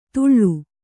♪ tuḷḷu